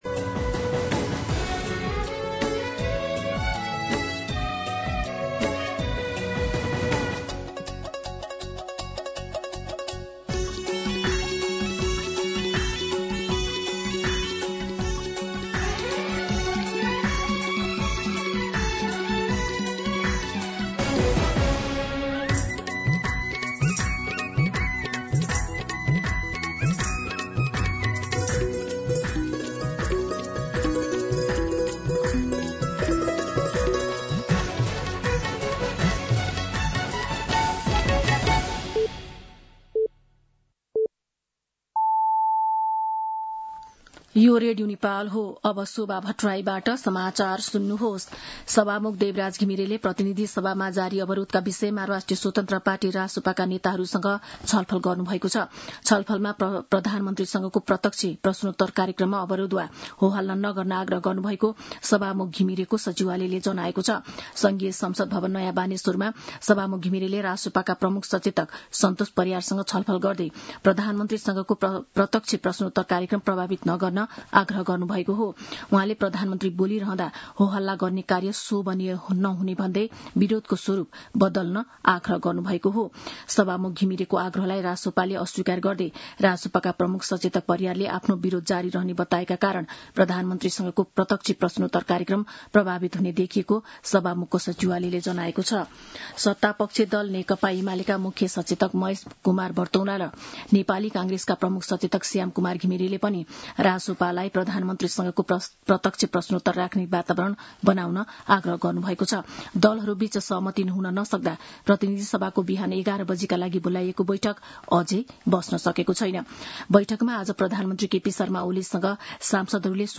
दिउँसो १ बजेको नेपाली समाचार : ६ असार , २०८२